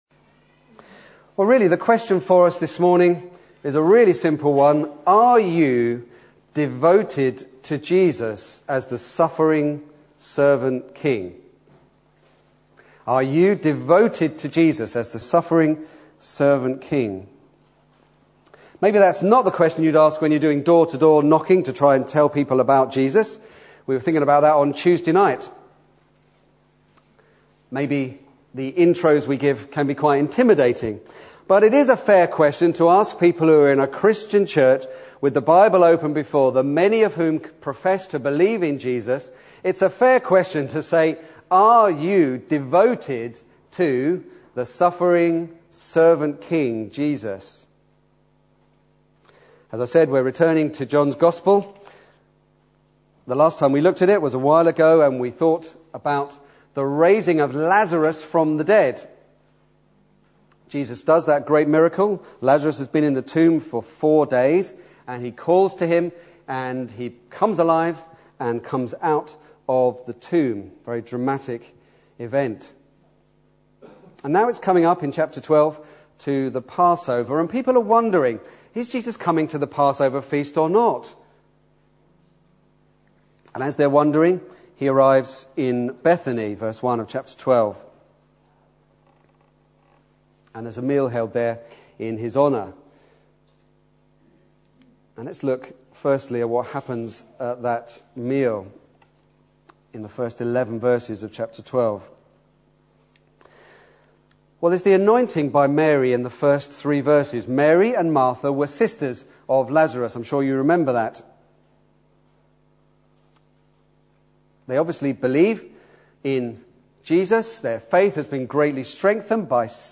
Back to Sermons The King’s Entry